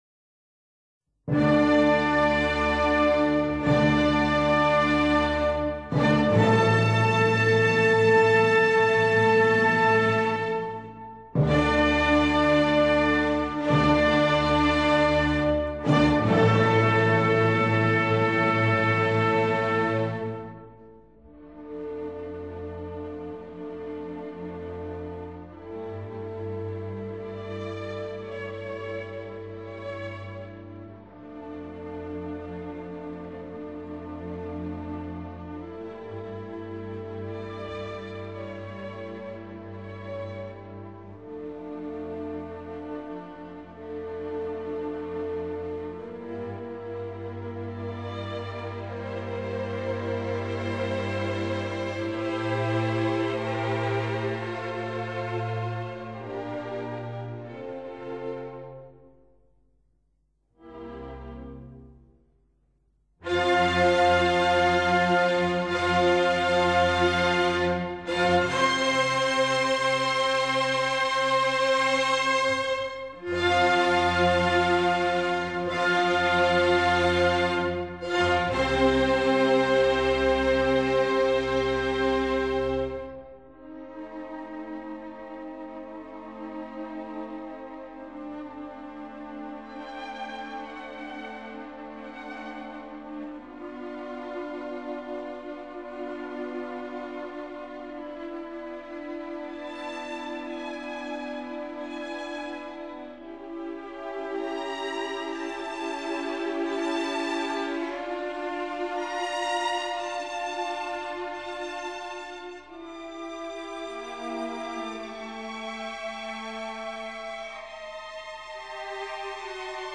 01-no-104-d-major-adagio.wma